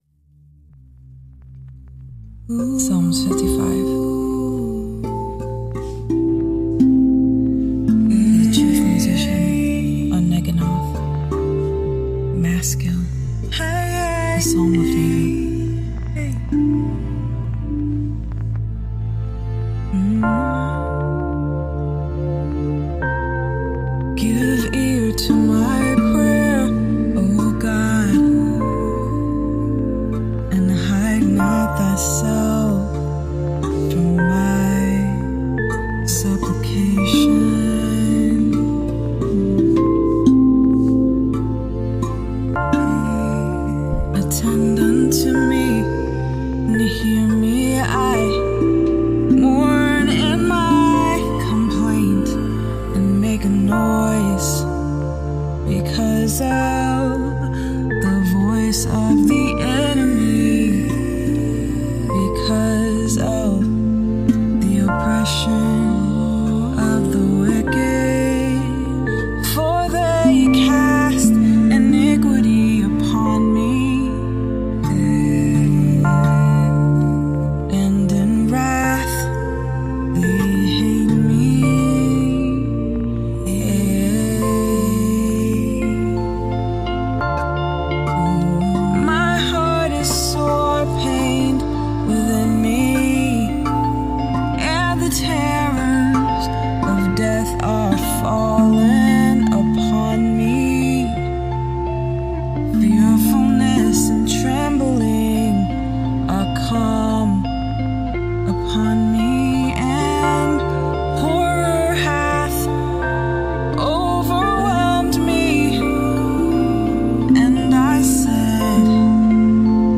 Rav vast drum